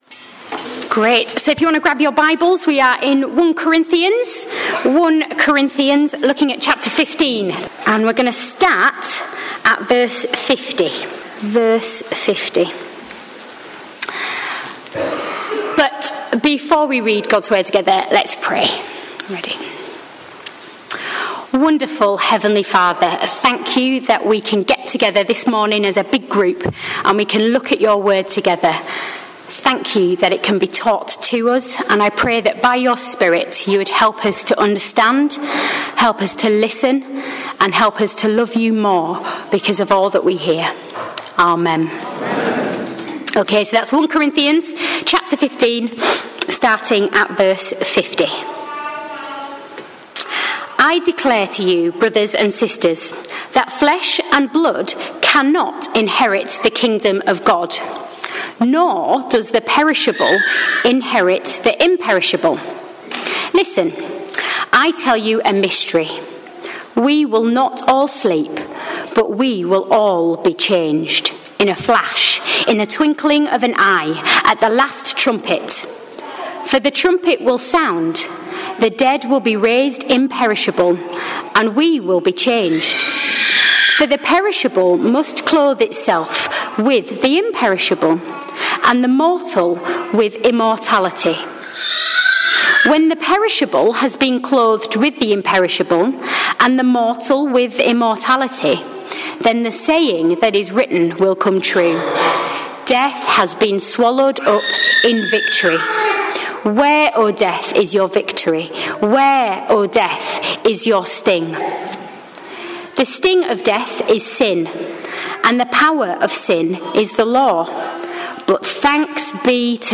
Sermons Archive - Page 68 of 188 - All Saints Preston